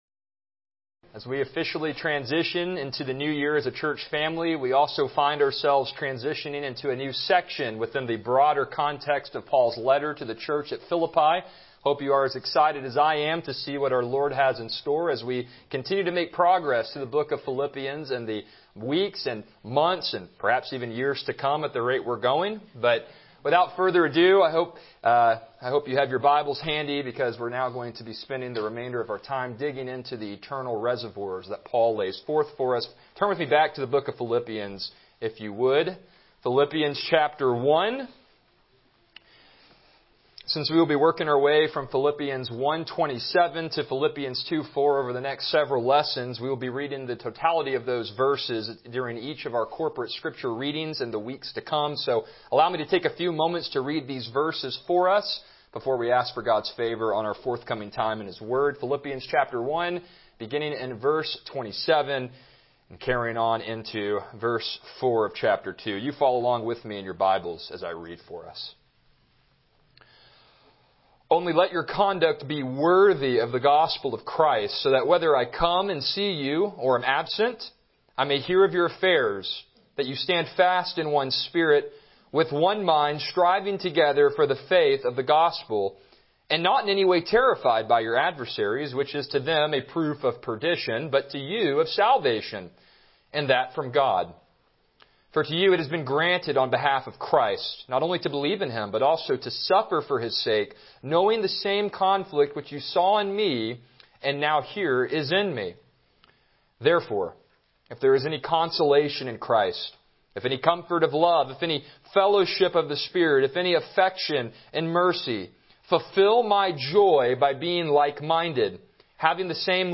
Epistle of Joy Passage: Philippians 1:27 Service Type: Morning Worship « Which Path Will You Choose?